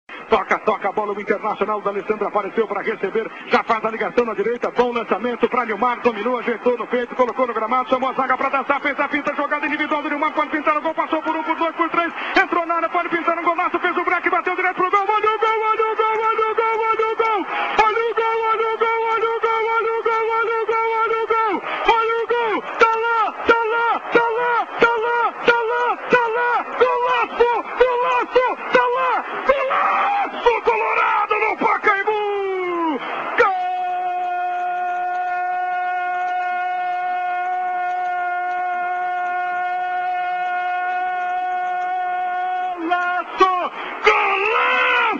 Бразильский футбольный комментатор
Голос бразильского комментатора